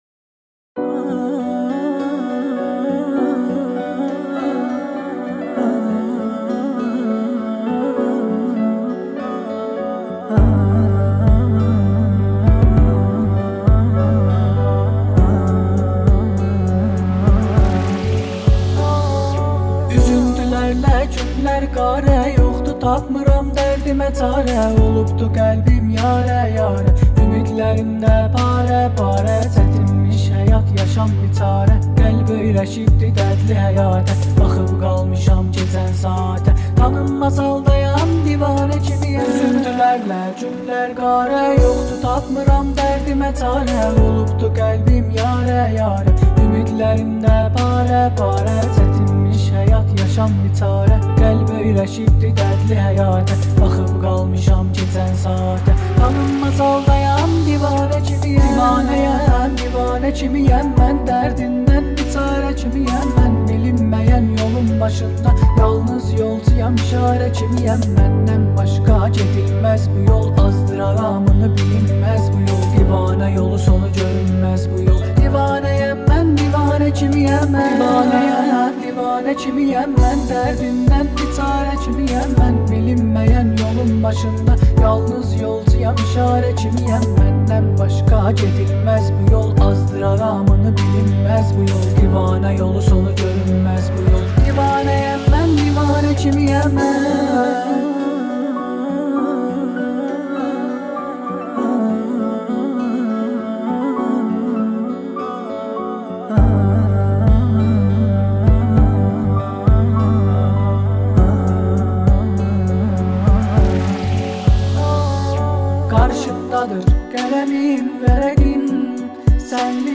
اهنگ ترکی